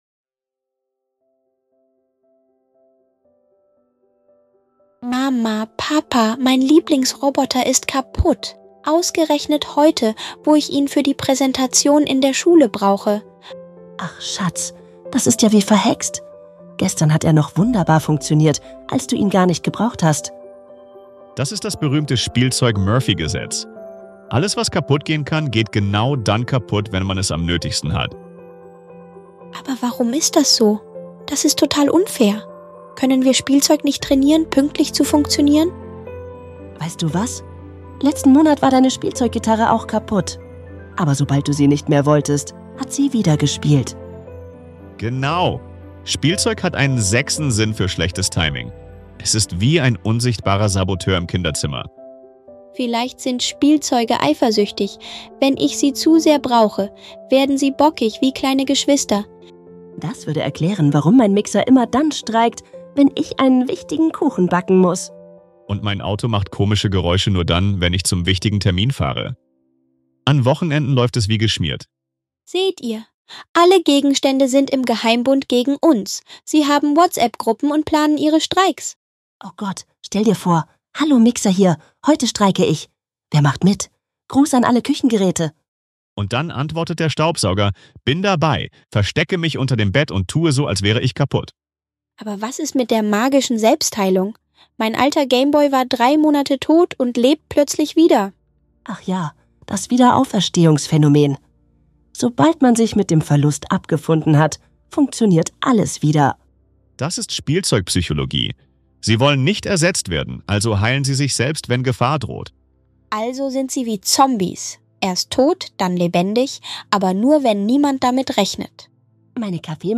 In diesem lustigen Gespräch zwischen Mama,